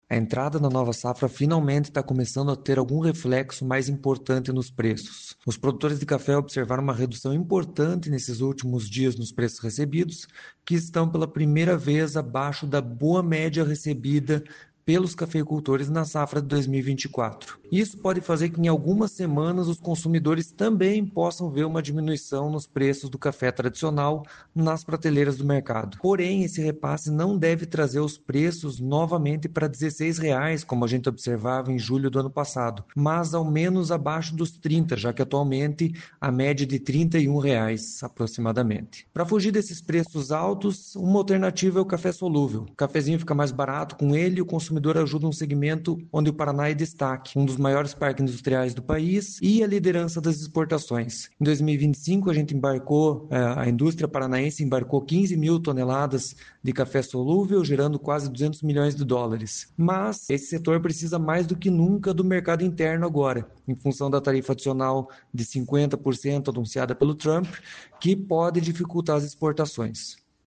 Sonora do agrônomo do Deral